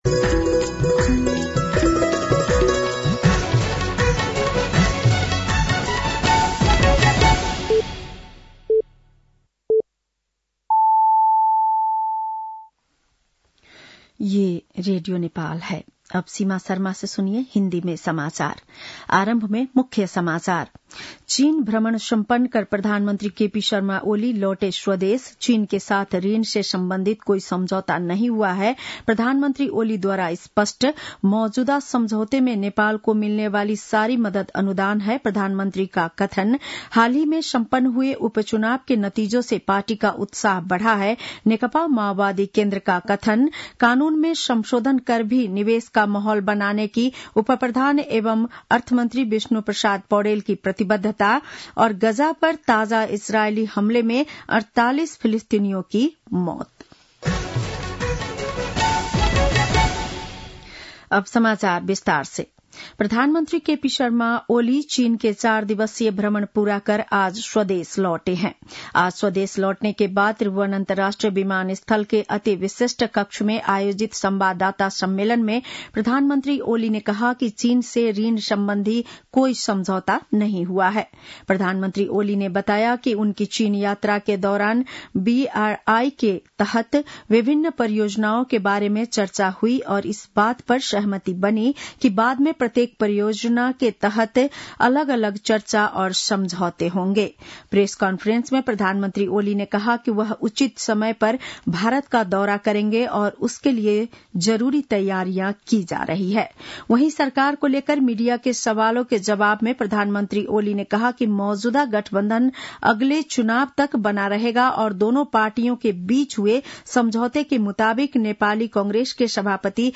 बेलुकी १० बजेको हिन्दी समाचार : २१ मंसिर , २०८१
10-PM-Hindi-NEWS-8-20.mp3